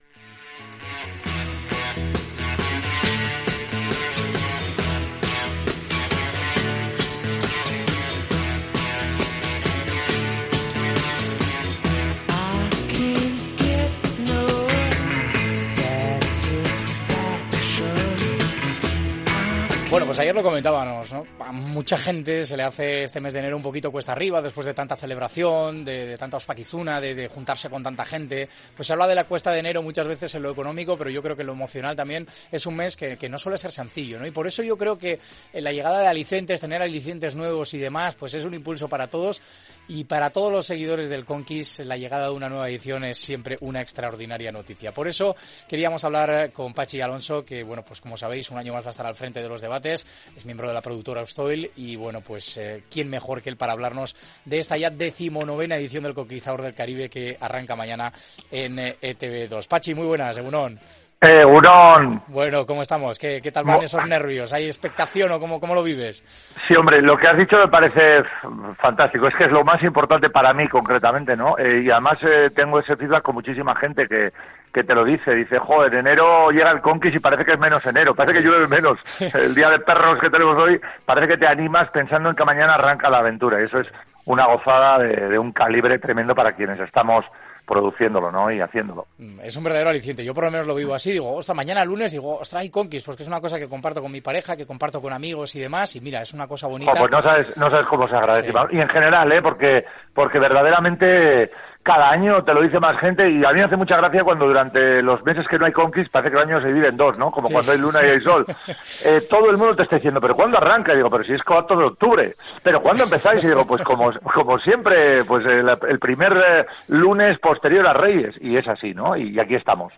No te pierdas la charla que hemos tenido con él en Onda Vasca.